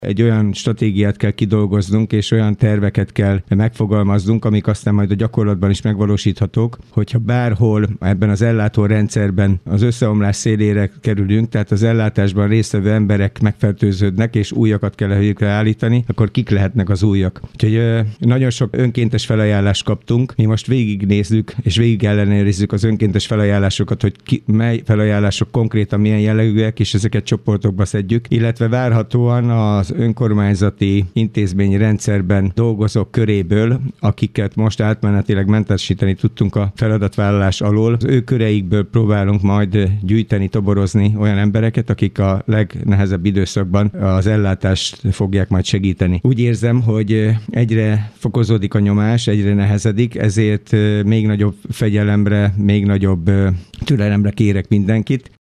A nyomás egyre nehezedik, ezért Dabason már elkezdődött azon emberek toborzása, akik az első vonalban fellépő emberek után beállnak a helyükre. Kőszegi Zoltán polgármestert hallják.